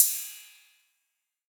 808CY_8_Orig_ST.wav